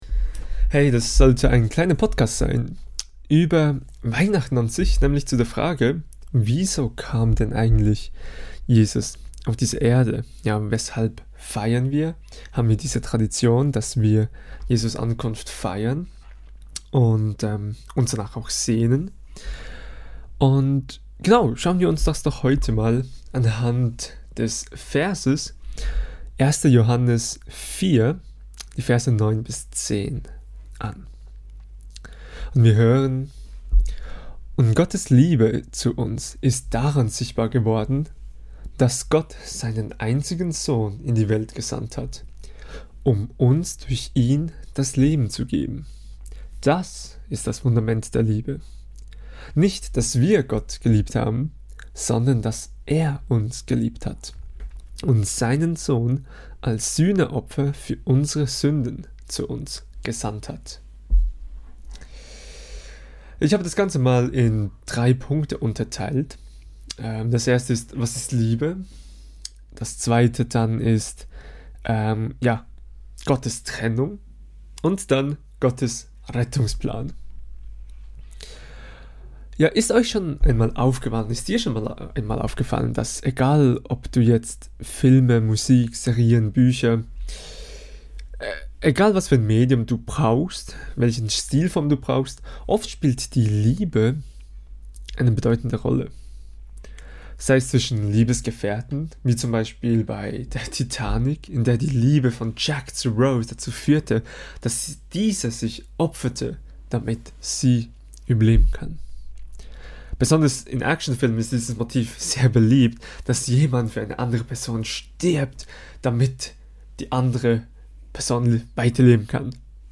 Kategorie: Predigt Prediger